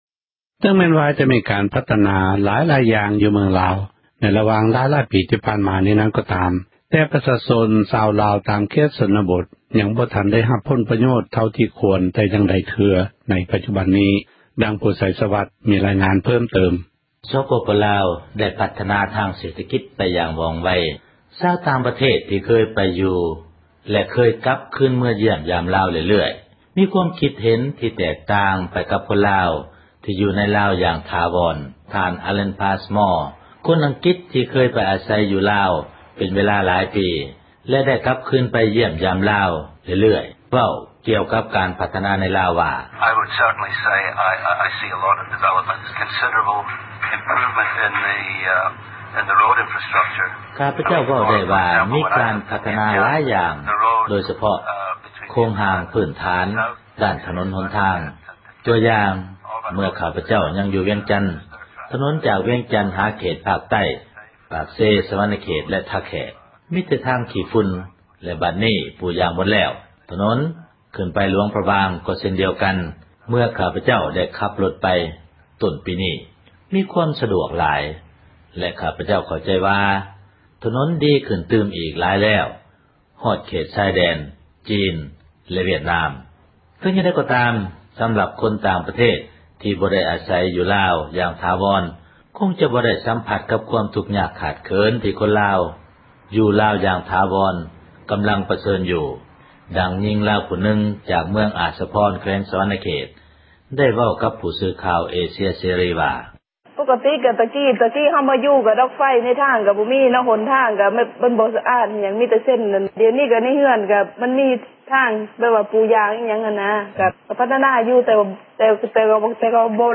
ດັ່ງຍິງລາວ ຜູ້ນຶ່ງຈາກ ເມືອງອາສພອນ ແຂວງ ສວັນນະເຂດ ໄດ້ເວົ້າກັບ ຜູ້ສື່ຂ່າວ ເອເຊັຍເສຣີວ່າ: